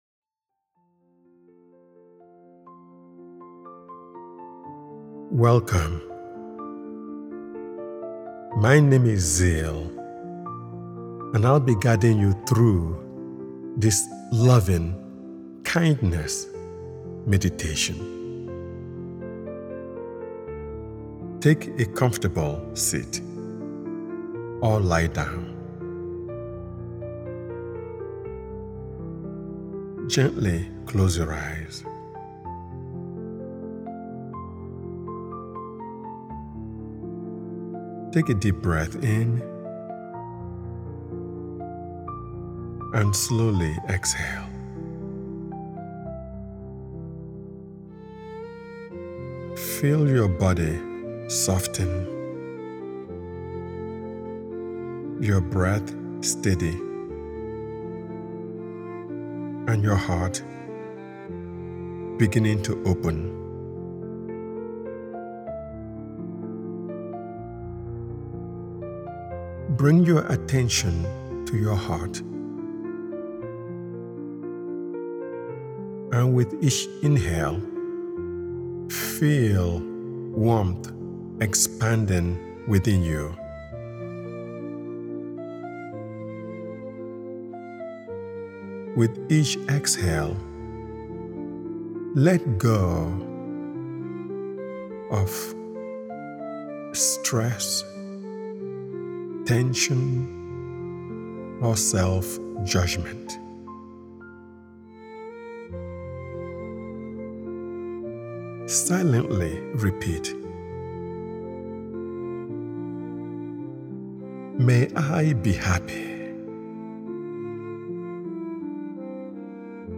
Loving-Kindness Meditation (Metta Prayer): A Meditation for Heart Awakening is a gentle, compassionate guided experience created to help listeners reconnect with the healing power of love, empathy, and emotional openness. Rooted in the ancient Buddhist practice of Metta, this meditation invites you to cultivate a warm, expansive sense of goodwill — first toward yourself, then toward people you care about, and finally toward all living beings.